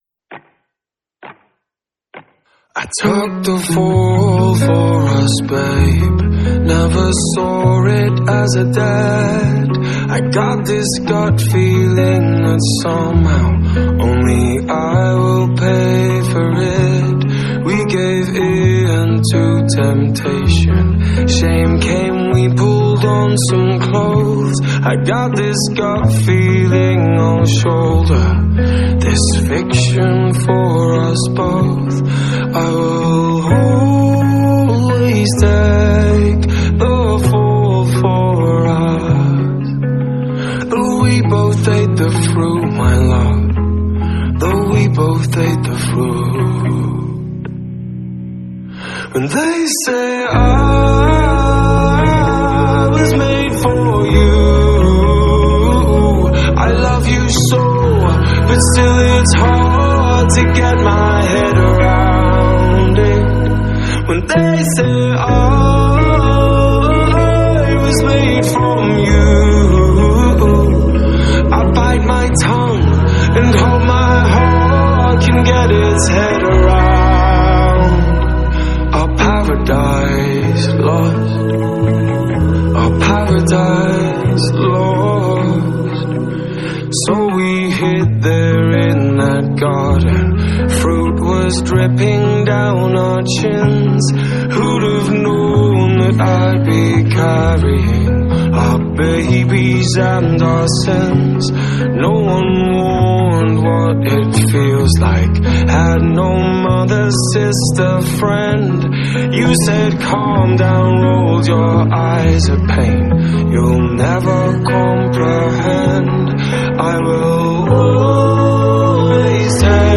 Alternative Rock, Synth-Pop, Orchestral